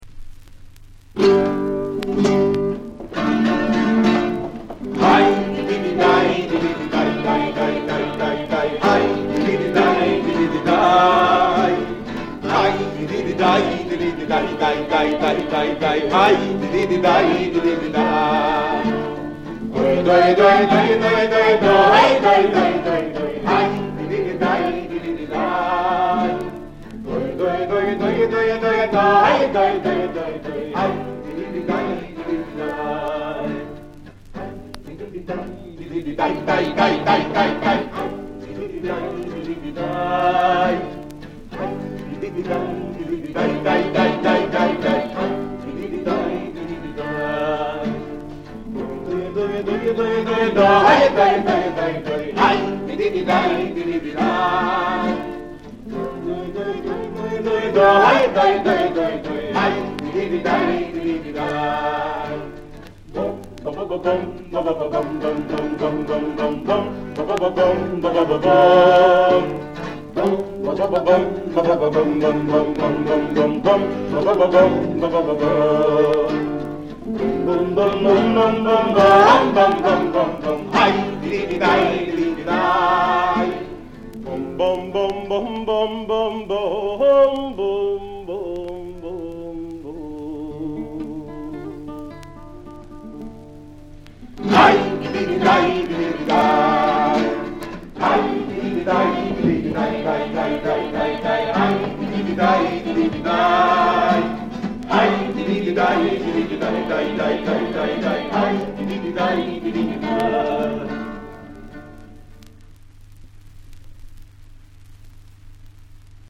I Nigunim sono eseguiti in uno stile vocale espressivo.